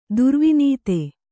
♪ durvinīte